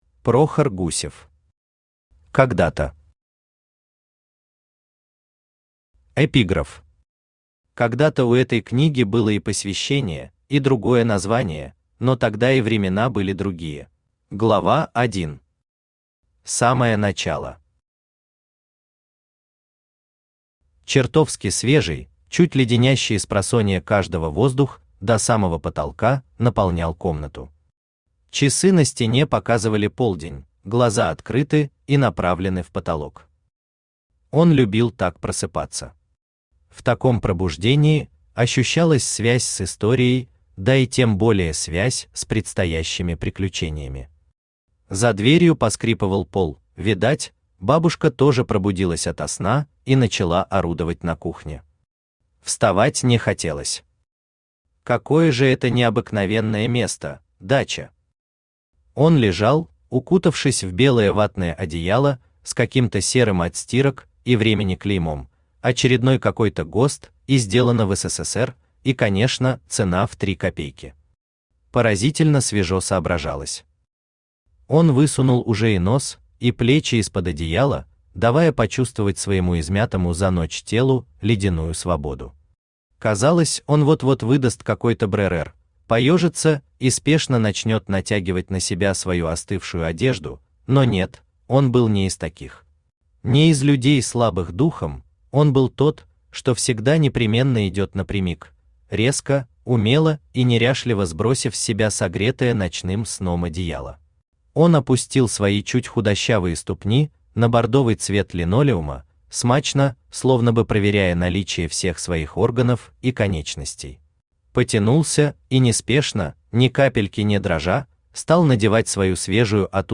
Аудиокнига Когда-то | Библиотека аудиокниг
Aудиокнига Когда-то Автор Прохор Гусев Читает аудиокнигу Авточтец ЛитРес.